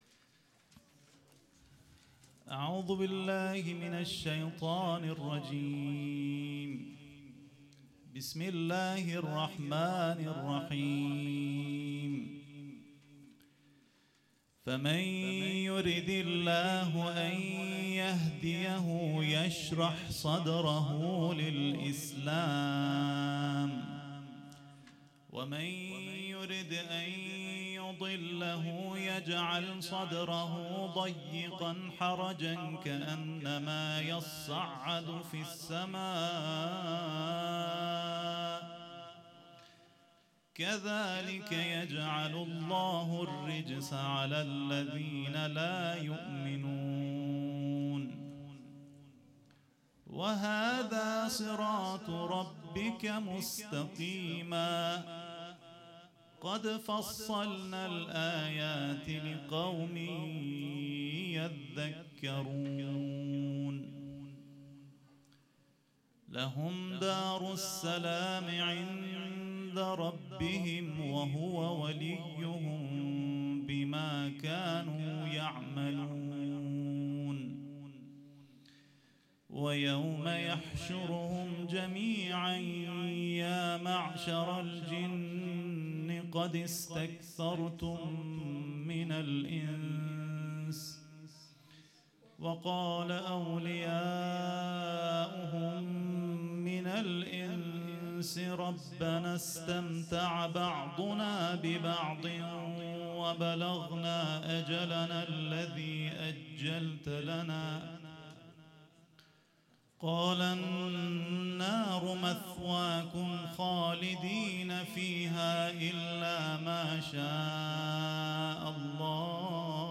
به گزارش خبرنگار ایکنا، نخستین محفل تخصصی حفظ قرآن، به همت مجمع قرآنیان اسلامشهر و با حضوری تنی چند از حافظان کل قرآن برگزار شد.
به تلاوت یک آیه از قرآن با روایت‌های مختلف پرداخت و در نهایت هم فرازهایی از قرآن را به سبک مرحوم استاد محمدصدیق منشاوی تلاوت کرد
حافظ کل قرآن